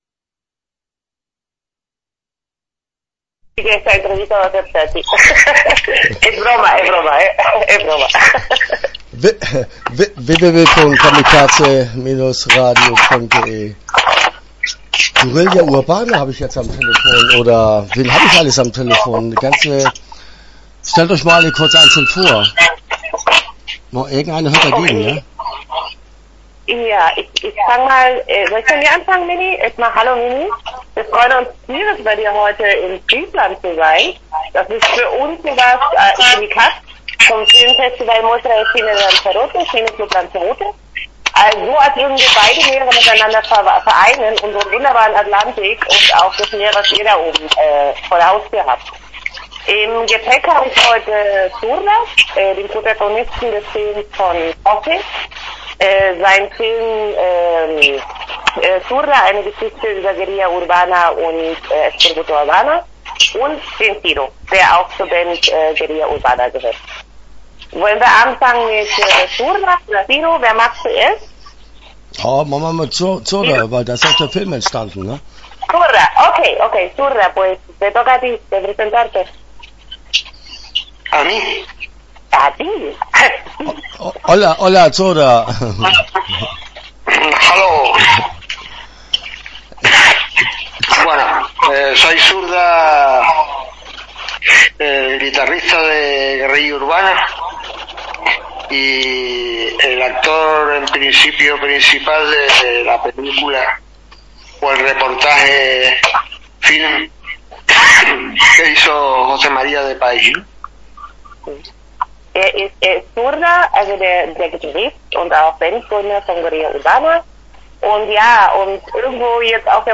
Guerrilla Urbana - Interview Teil 1 (21:38)